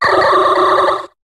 Cri de Rosabyss dans Pokémon HOME.